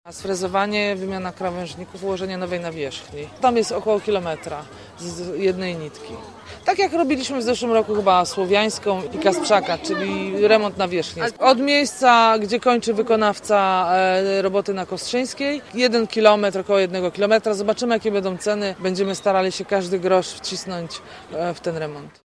Remont dotyczyć ma tylko jednego pasa ruchu w stronę centrum miasta. Mówi wiceprezydent Agnieszka Surmacz: